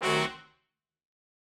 GS_HornStab-Cdim.wav